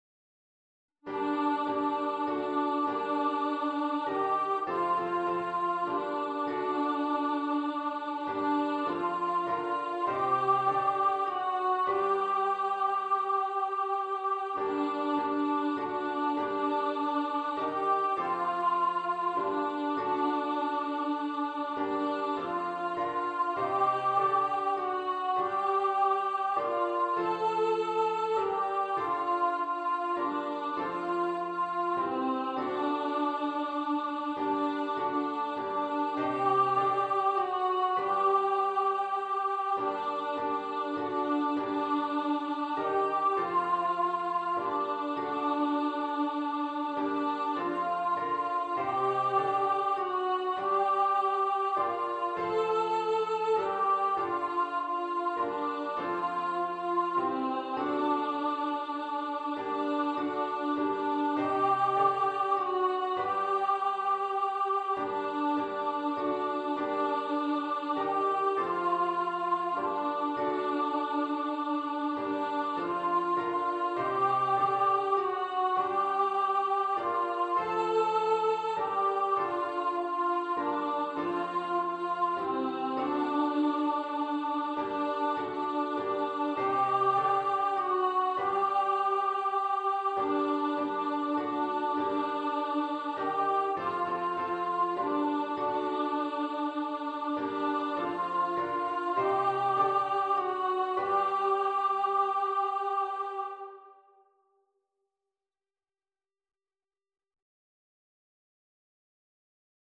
Coventry-Carol-Alto.mp3